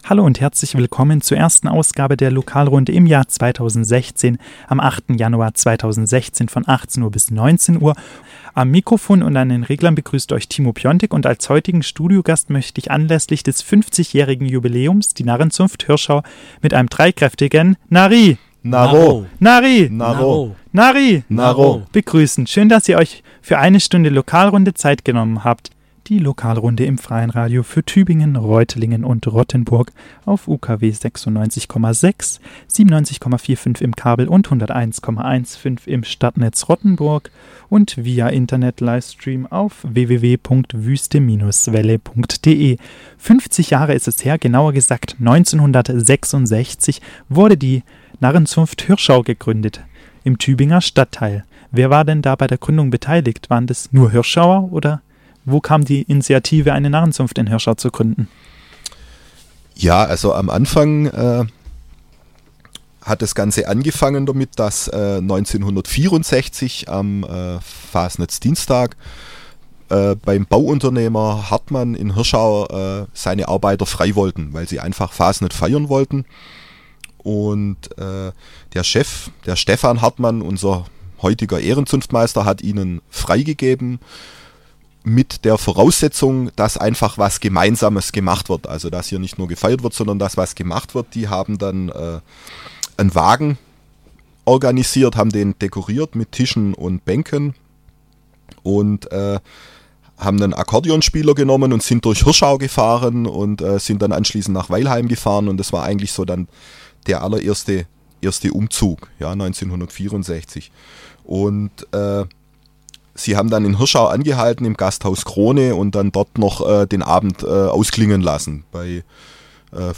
Das 50-Jährige Bestehen feiert die Narrenzunft Hirschau dieses Jahr und hat erstmals ein Radiostudio gestürmt.